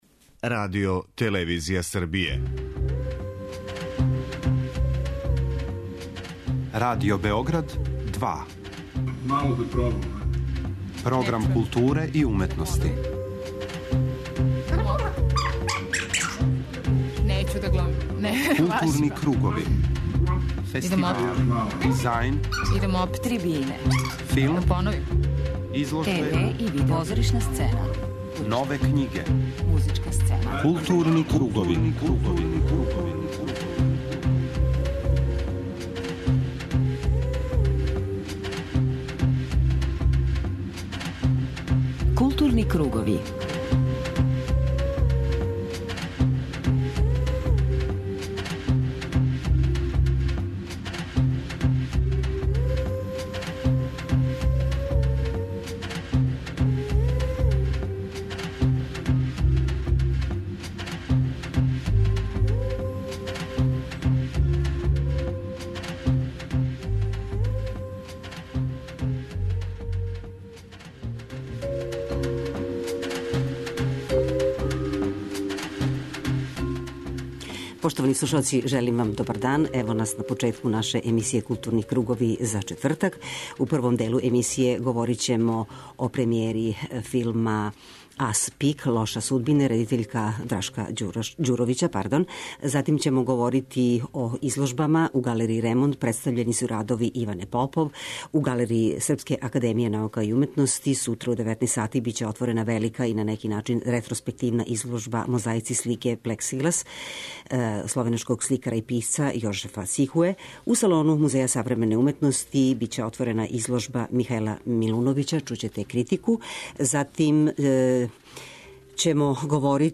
преузми : 52.97 MB Културни кругови Autor: Група аутора Централна културно-уметничка емисија Радио Београда 2.